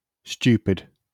IPA/ˈst(j)uːpɪd/, SAMPA/"st(j)u:pId/
wymowa amerykańska?/i